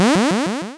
Add sound effect assets.
select.wav